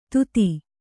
♪ tuti